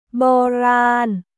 ボーラーン